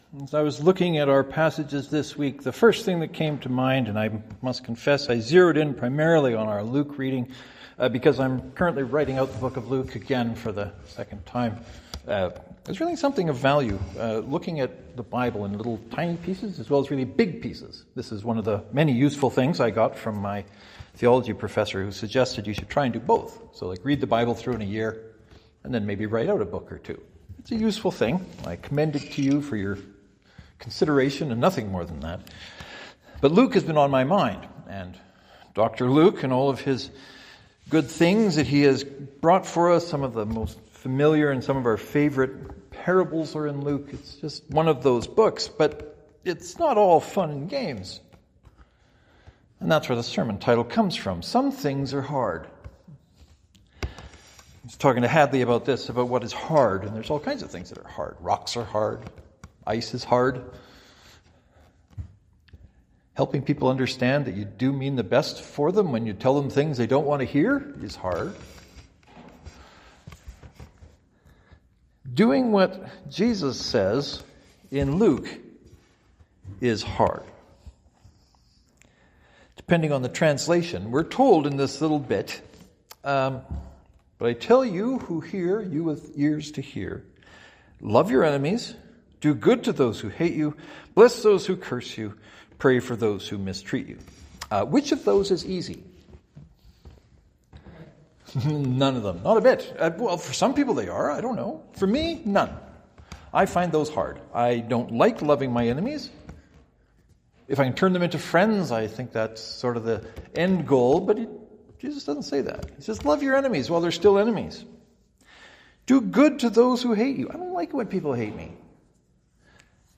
I begin the sermon with a short list of things that are hard: rocks, ice, etc. We all know about hard things.